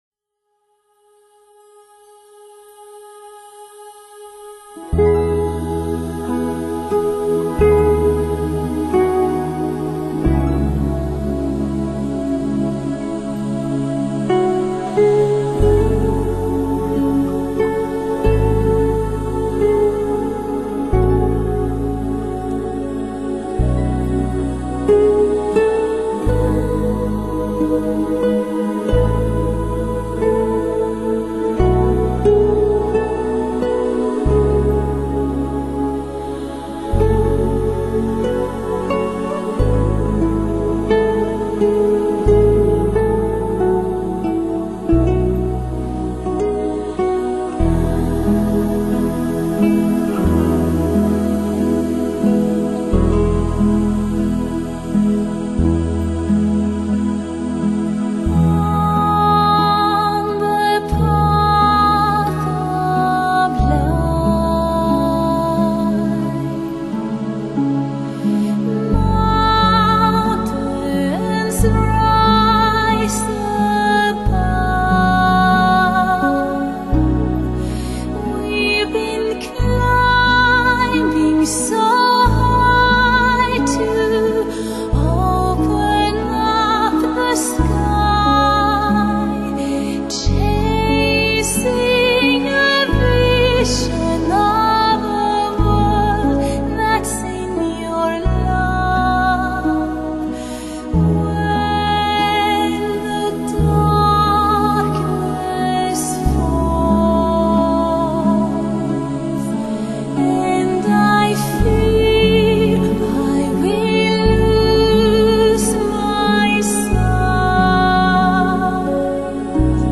极地寒冷的气候造就了她如水晶般清澈的嗓音。
坚实的声乐基础使她的音域能轻松跨越3个8度而游刃有余。